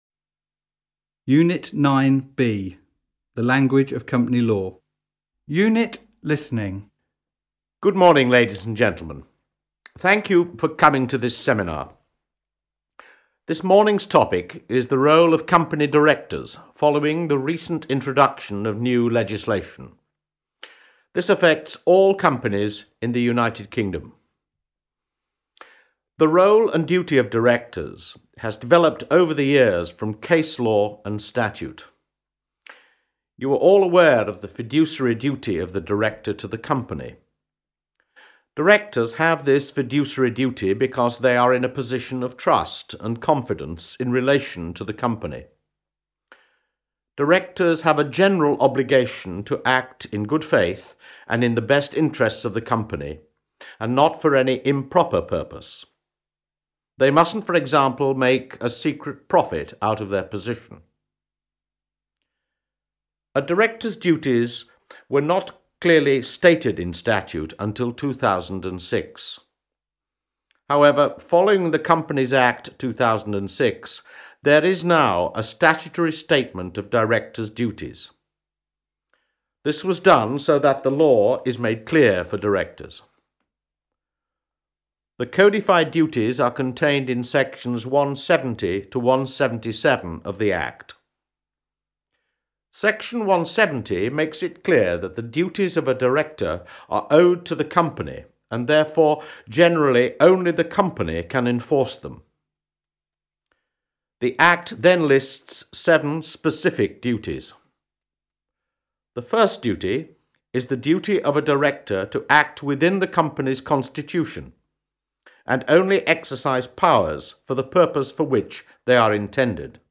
Listening Activity